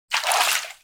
fishcatch.wav